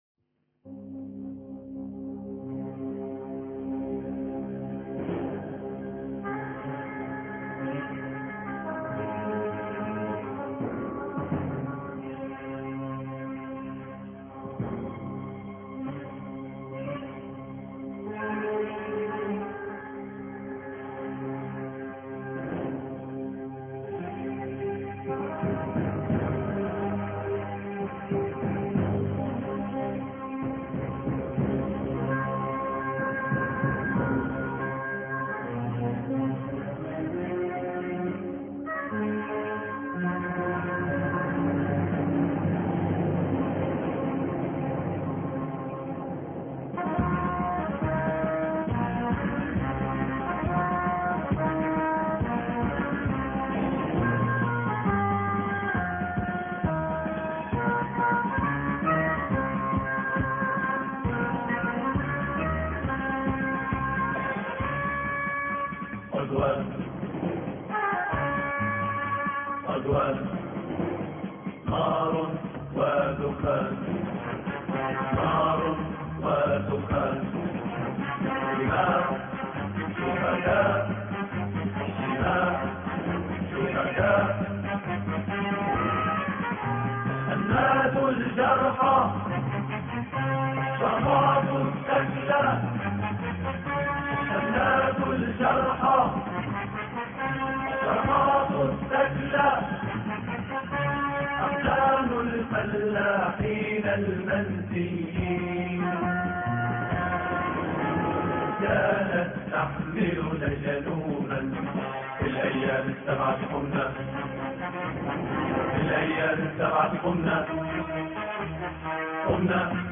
عدوان، نار ودخان الإثنين 21 إبريل 2008 - 00:00 بتوقيت طهران تنزيل الحماسية شاركوا هذا الخبر مع أصدقائكم ذات صلة الاقصى شد الرحلة أيها السائل عني من أنا..